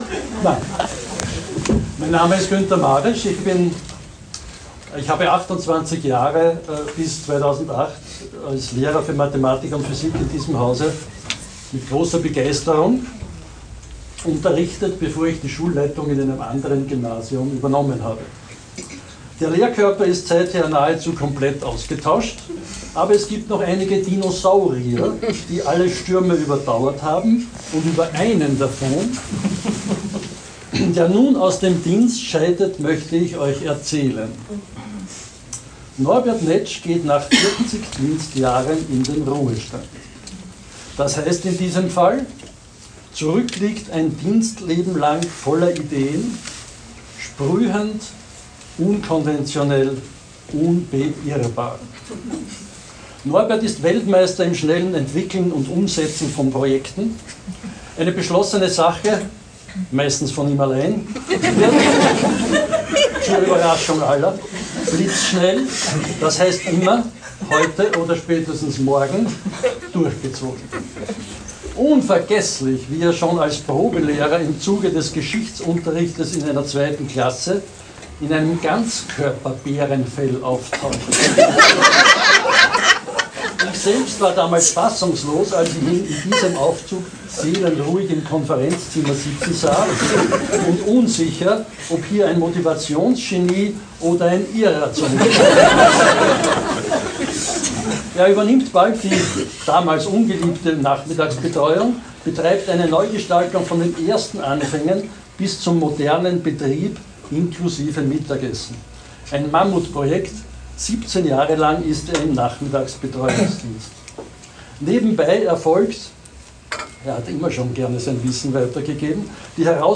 Abschiedsrede